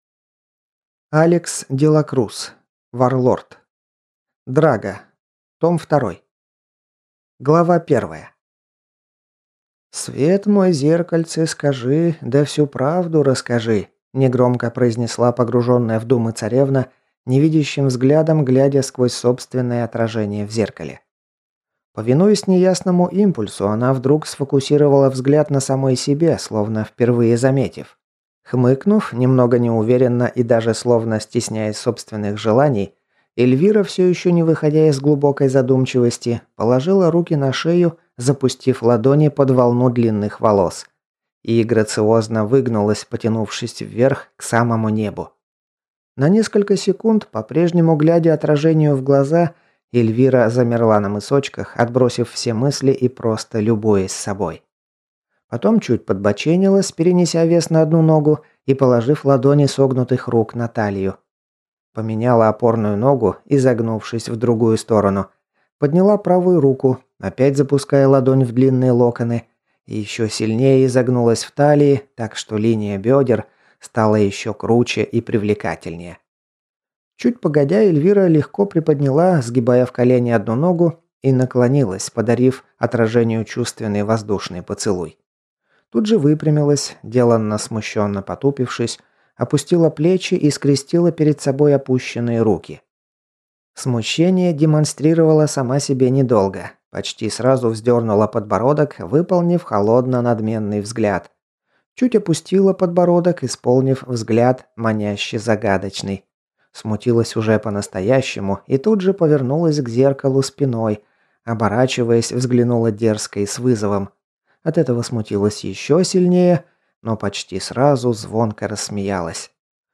Аудиокнига Варлорд. Драго. Том II | Библиотека аудиокниг